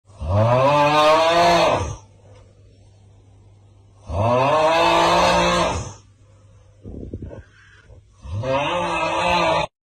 Tiktok Augh Sound Effect
fun laugh laughter sound effect free sound royalty free Funny